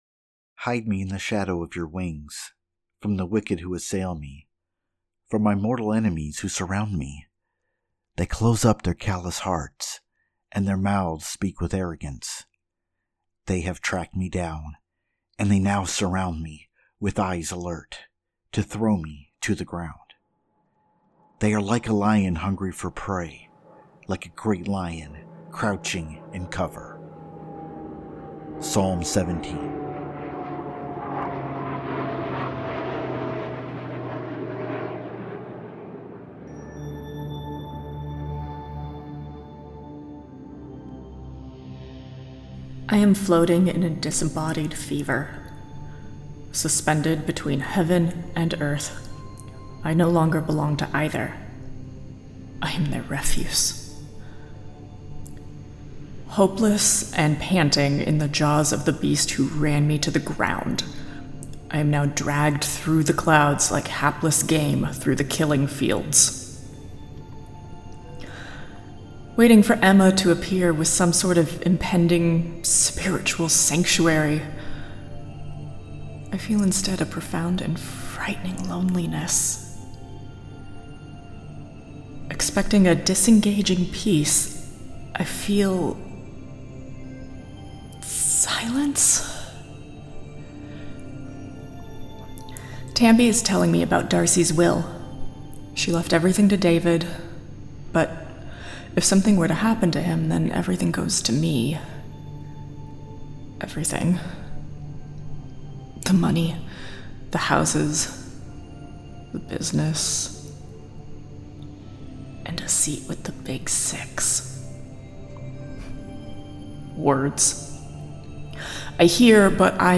Strangers In Paradise – The Audio Drama – Book 8 – My Other Life – Episode 8 – Two True Freaks
The Ocadecagonagon Theater Group